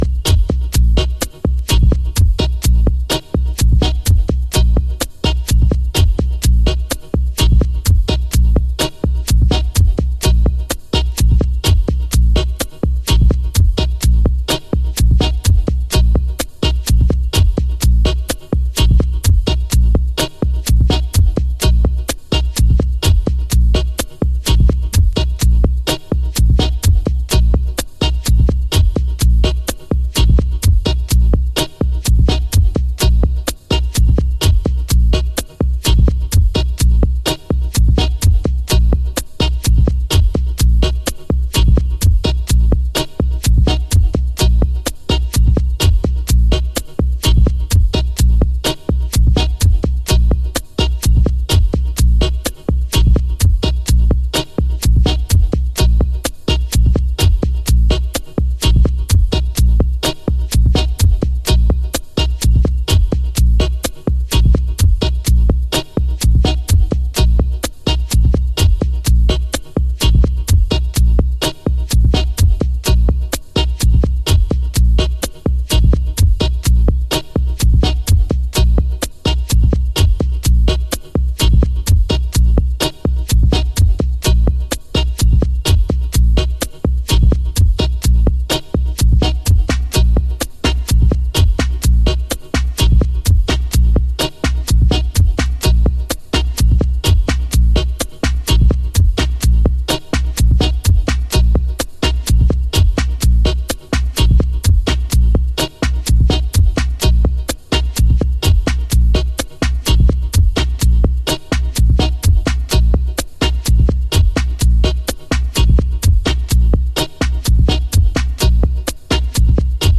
Early House / 90's Techno
BASSIC CHANNELの手法を応用し、装飾を一切省き骨組みだけで構成された彼岸の骨ミニマリズム。